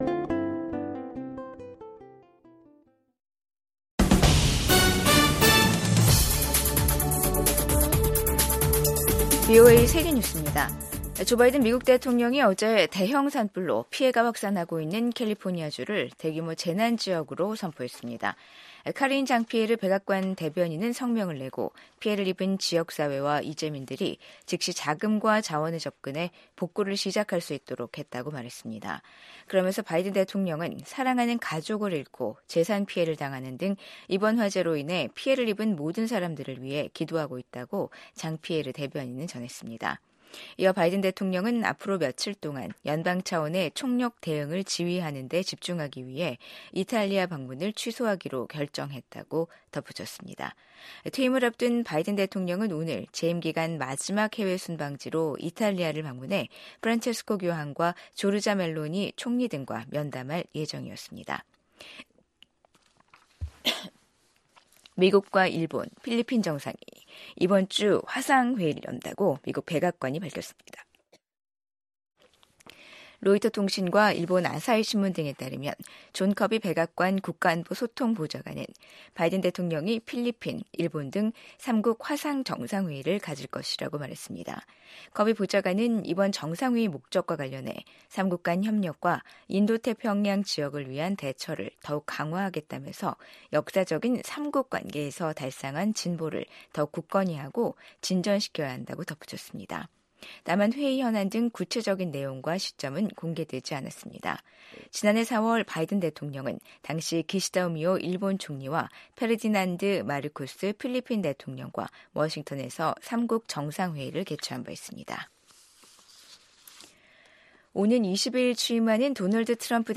VOA 한국어 간판 뉴스 프로그램 '뉴스 투데이', 2025년 1월 9일 2부 방송입니다. 미국 국방부는 최근 북한의 탄도미사일 발사를 인지하고 있으며 동맹과 긴밀히 협력하고 있다고 밝혔습니다.